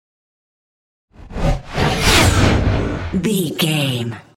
Scifi whoosh pass by
Sound Effects
futuristic
pass by
vehicle